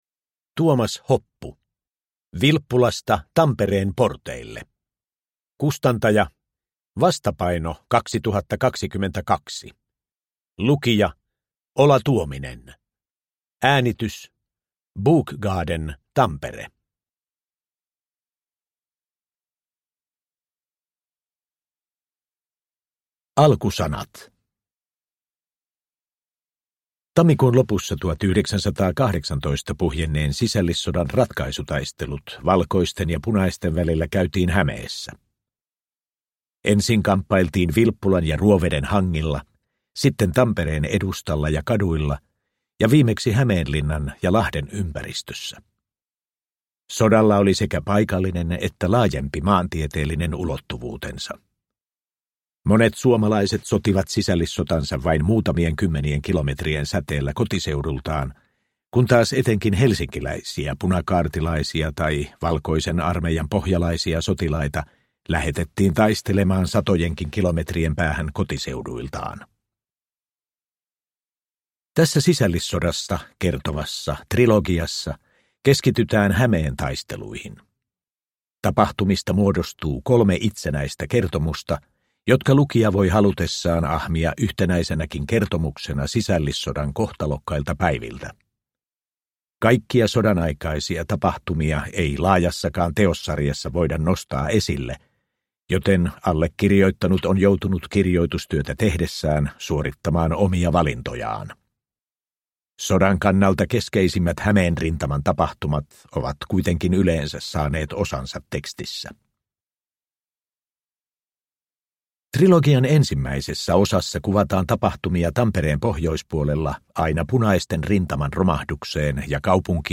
Vilppulasta Tampereen porteille – Ljudbok – Laddas ner